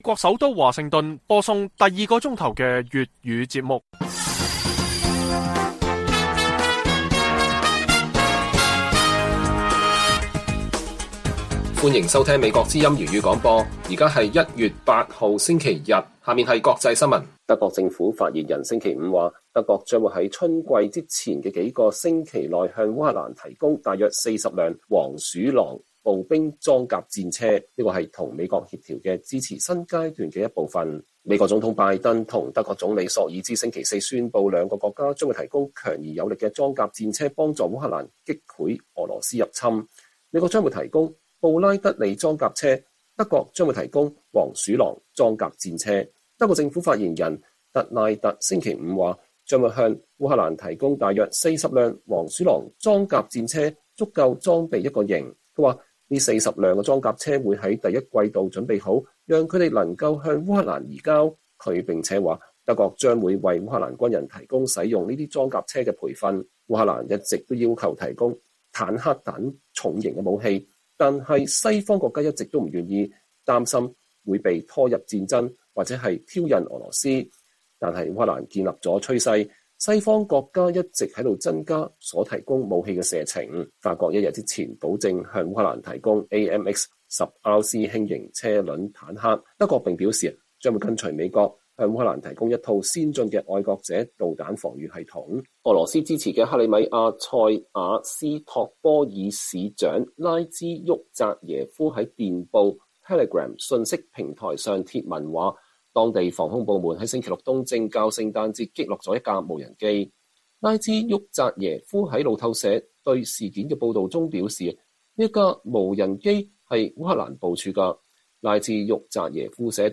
粵語新聞 晚上10-11點 : 推特上的中國：西方應該阻止中國的經濟增長嗎？觀察人士意見不一